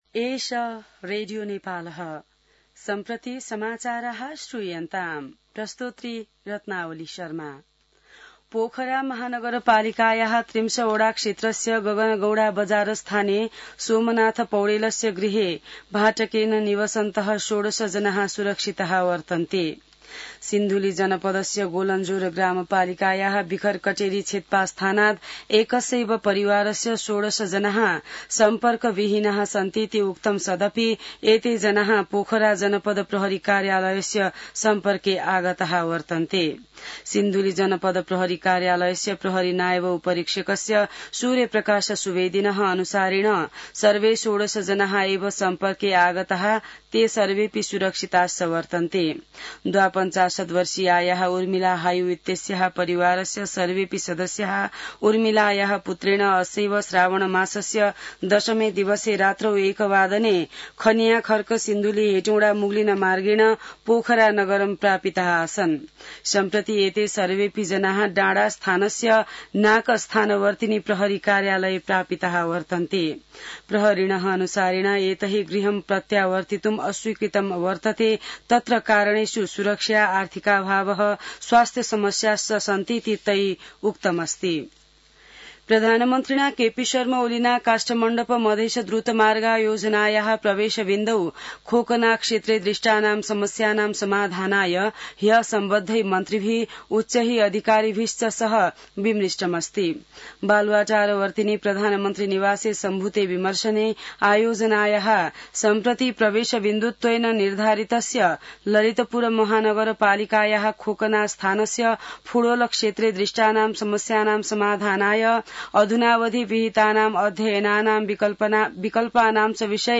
संस्कृत समाचार : १३ साउन , २०८२